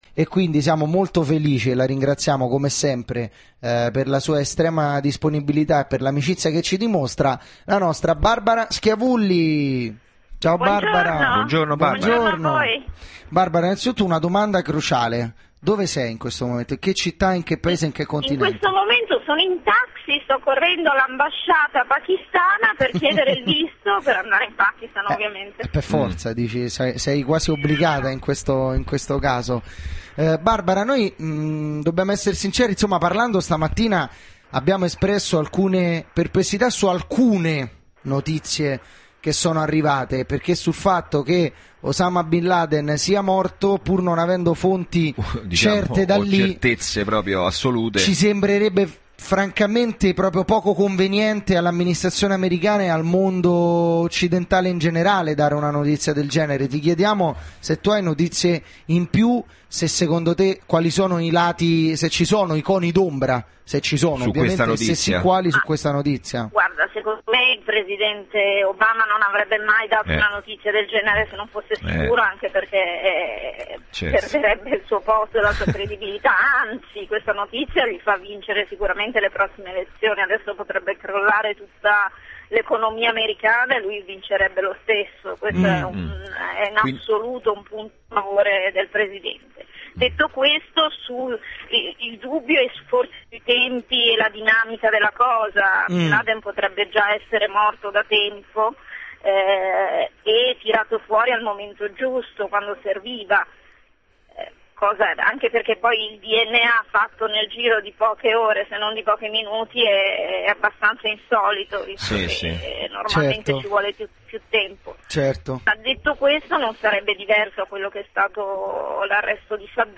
Intervento telefonico
Giornalista e reporter di guerra in collegamento telefonico per commentare la notizia della morte di Osama Bin Laden